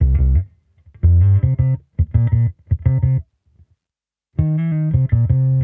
Index of /musicradar/sampled-funk-soul-samples/85bpm/Bass
SSF_PBassProc2_85C.wav